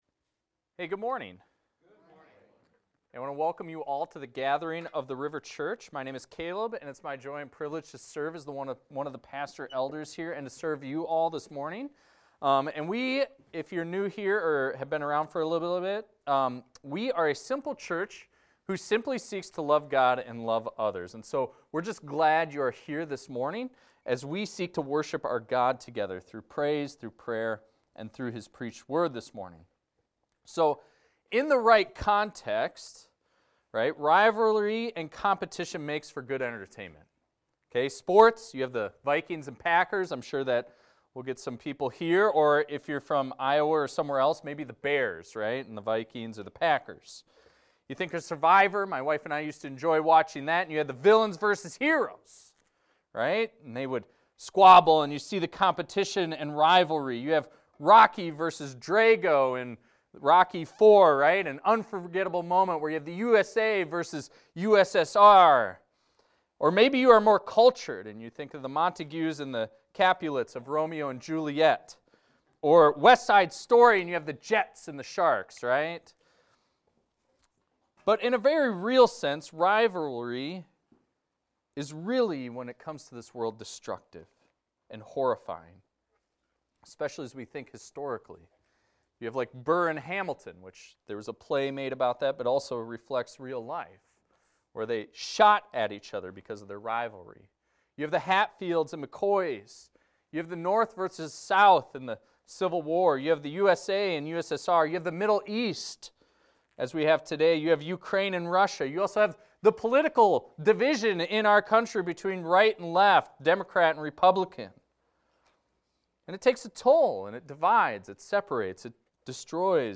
This is a recording of a sermon titled, "Dysfunctional Baby Race."